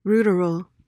PRONUNCIATION:
(ROO-duhr-uhl)